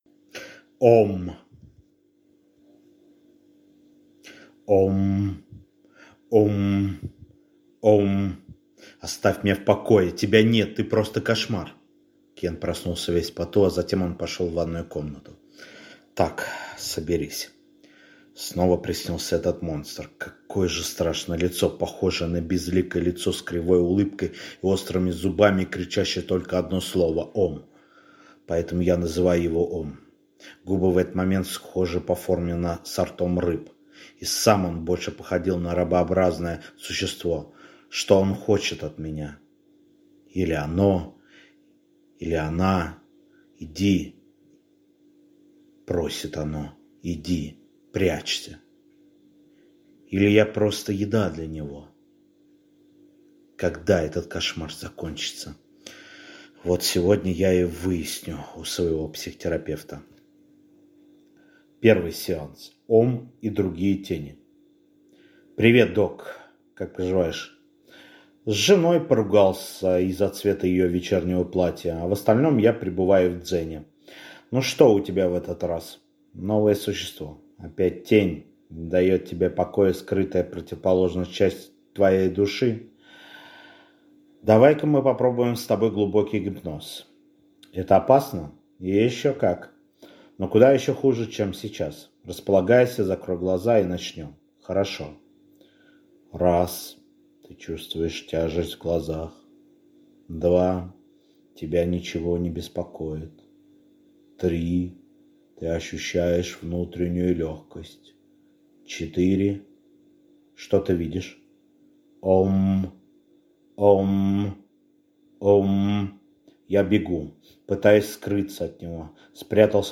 Аудиокнига Ом | Библиотека аудиокниг